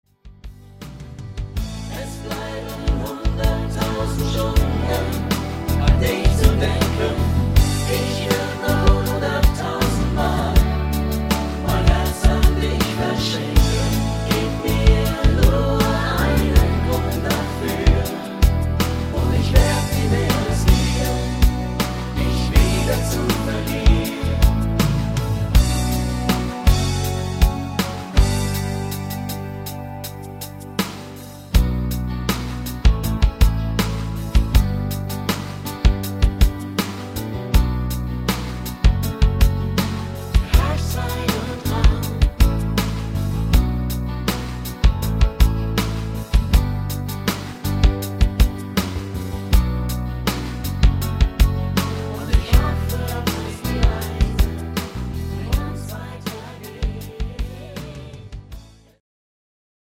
Rhythmus  Slow
Art  Deutsch, Pop, Rock